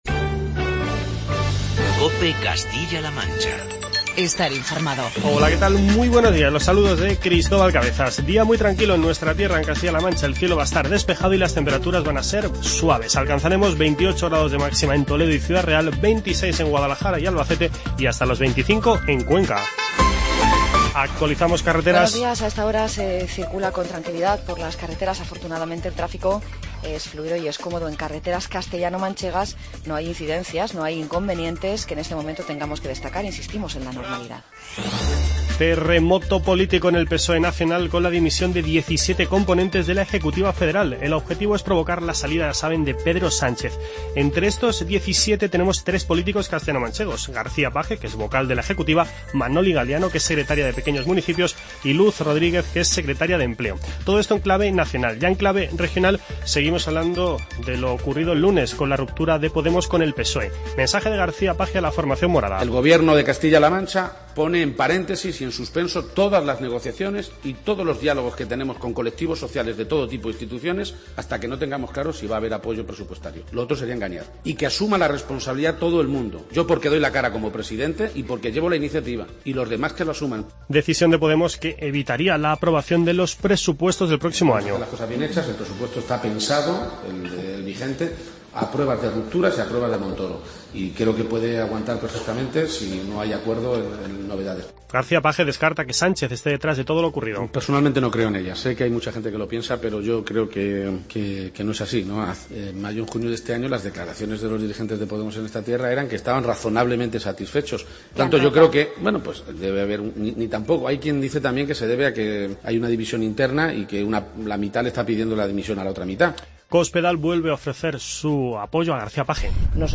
Escuche en nuestras "Voces de los Protagonistas" las declaraciones de Emiliano García-Page y María Dolores Cospedal.